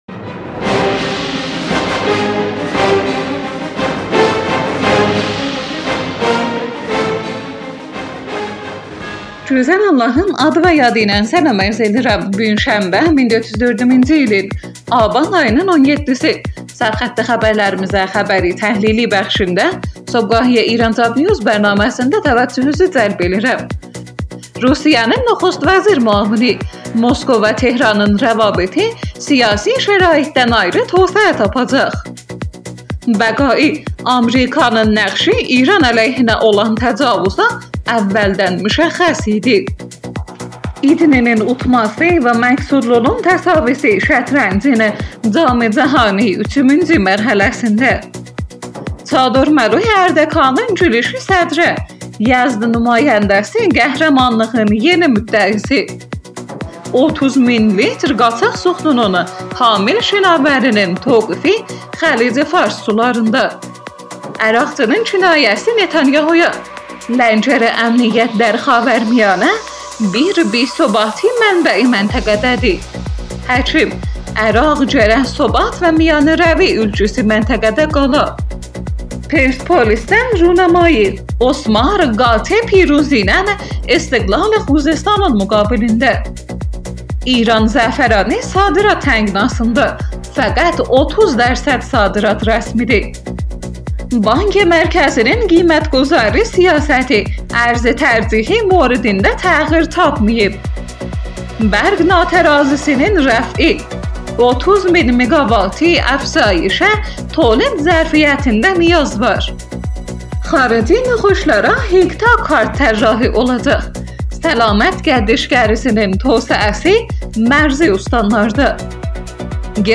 Iranjobnews Səhər xəbərləri. شنبه ۱۷ آبان ۱۴۰۴ ترکی آذربایجانی